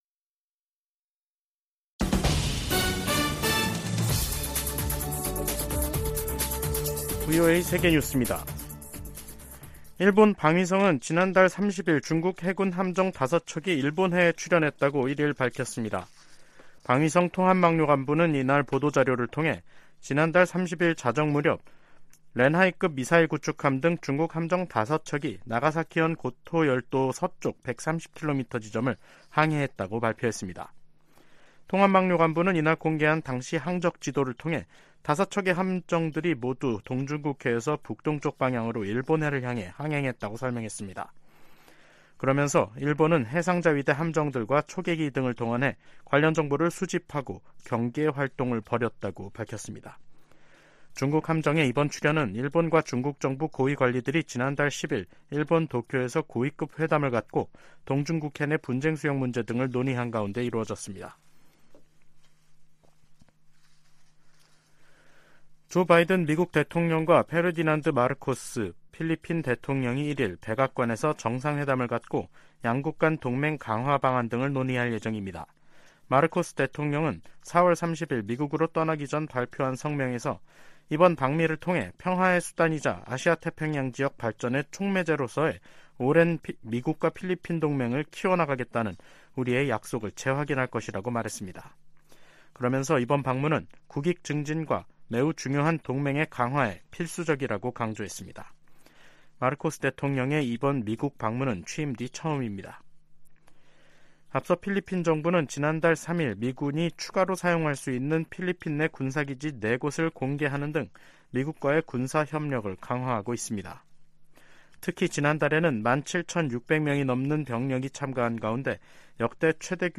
VOA 한국어 간판 뉴스 프로그램 '뉴스 투데이', 2023년 5월 1일 3부 방송입니다. 김여정 북한 노동당 부부장이 ‘결정적 행동’을 언급해 대형 도발에 나설 가능성을 시사했습니다. 윤석열 한국 대통령이 하버드대 연설에서 워싱턴 선언에 포함된 한국의 의무를 거론하며 독자 핵개발에 선을 그었습니다. 12년 만에 이뤄진 한국 대통령의 미국 국빈 방문은 한층 강화된 양국 관계를 보여줬다고 캐서린 스티븐스 전 주한 미국대사가 평가했습니다.